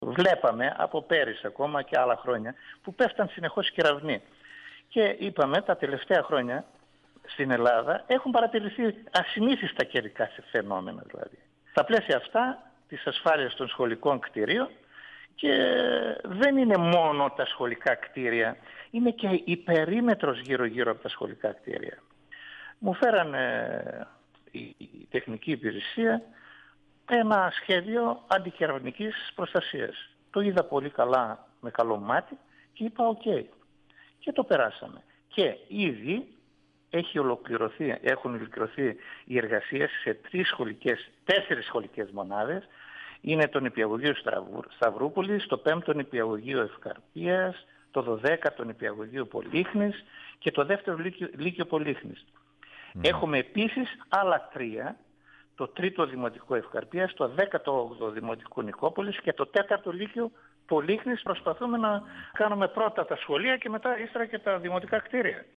Ο αντιδήμαρχος Τεχνικών Εργων του δήμου, Στέφανος Βάρφης,  στον 102FM του Ρ.Σ.Μ. της ΕΡΤ3
Συνέντευξη